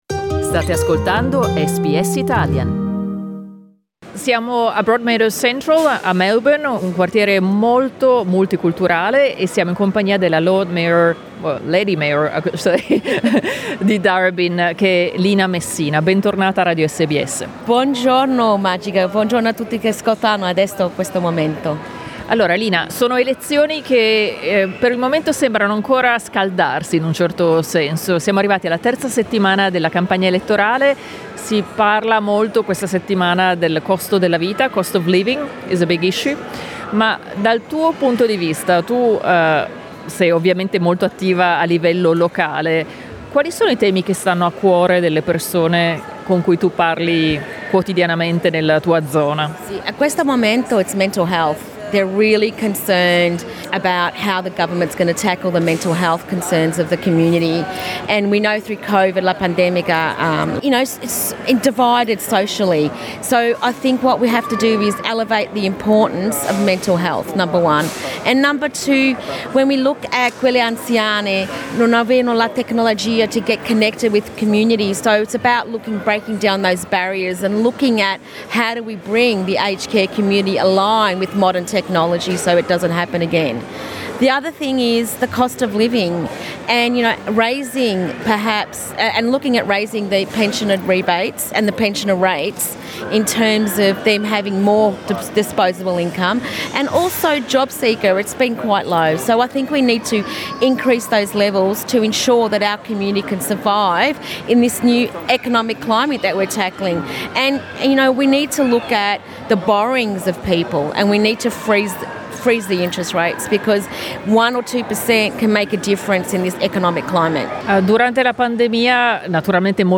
"Election Exchange" è un ciclo di incontri organizzato da SBS radio in varie città australiane, per incontrare candidate/i, esponenti delle organizzazioni comunitarie ed elettori/elettrici. Sabato 30 aprile SBS Radio ha tenuto uno degli incontri a Melbourne.
Ascolta l'intervista in inglese (con introduzione in italiano) a Lina Messina: